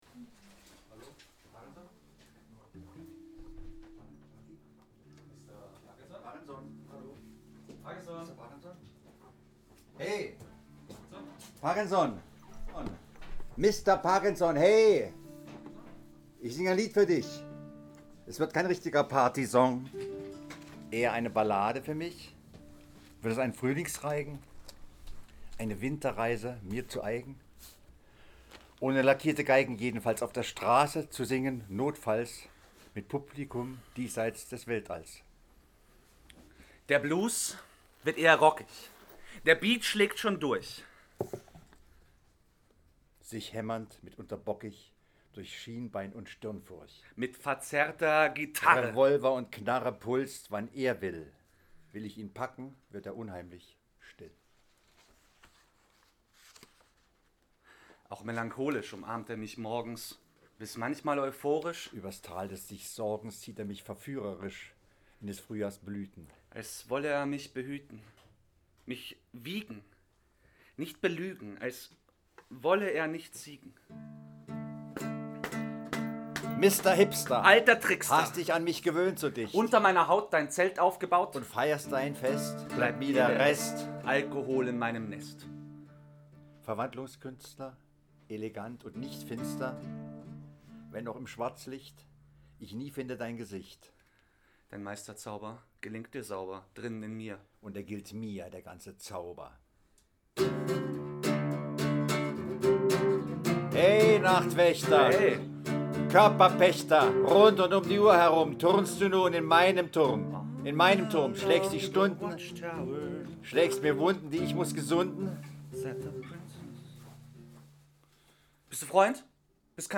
support guitar
Mitschnitte aus der Lesung in der Leipziger Buchhandlung Seitenblick (März 2018)